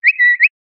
ノイズの無いクリアーな口笛の音。